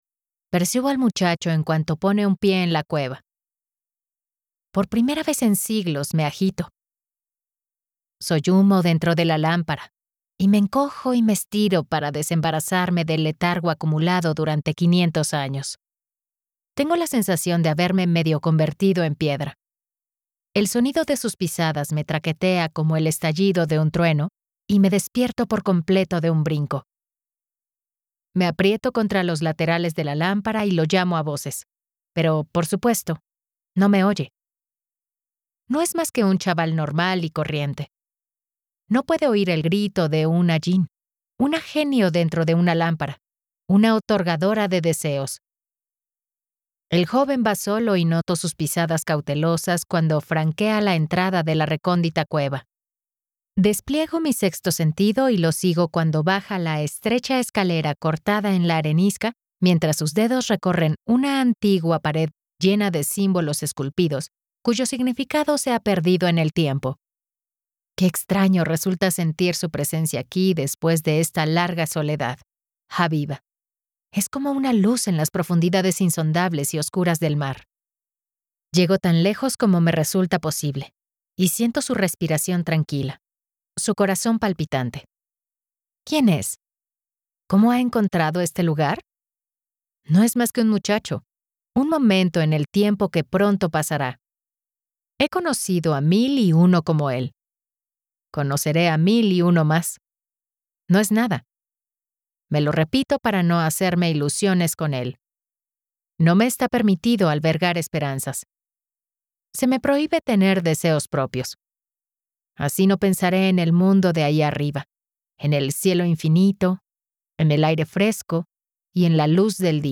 Audiolibro El tercer deseo (The Forbidden Wish)